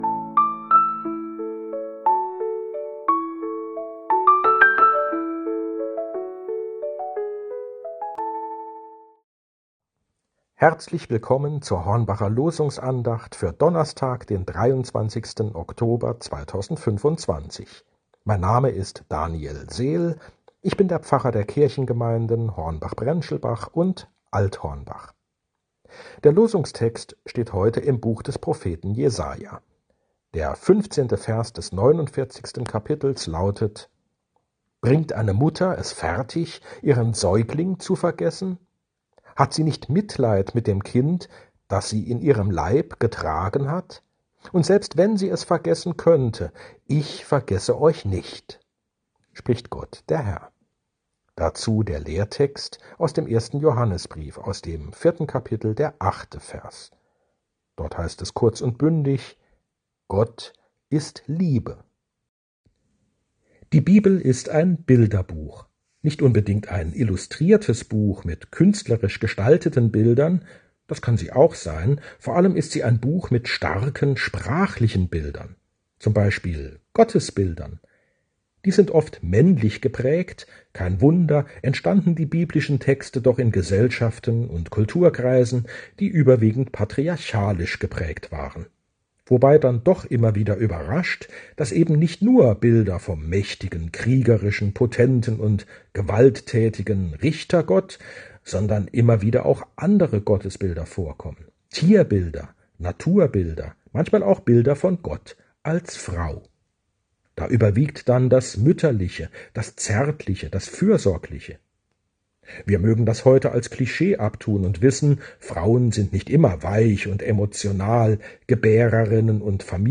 Losungsandacht für Donnerstag, 23.10.2025